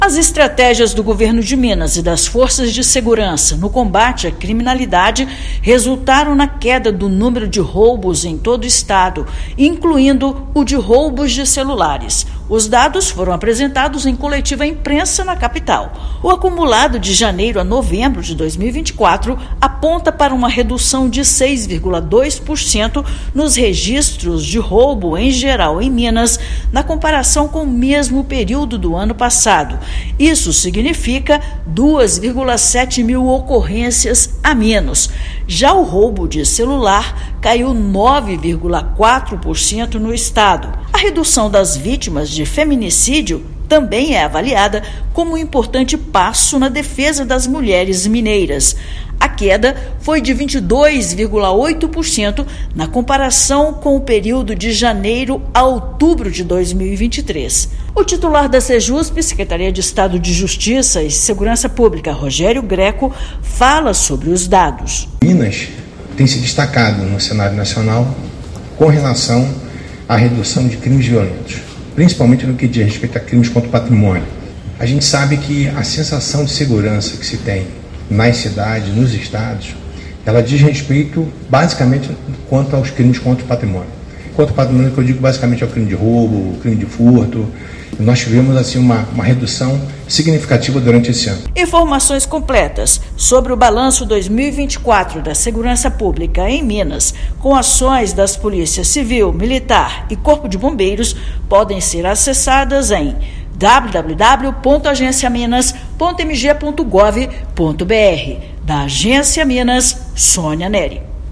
Balanço de 2024 das Forças de Segurança aponta para a detenção de quase 240 mil pessoas e apreensão de 70 toneladas de drogas. Ouça matéria de rádio.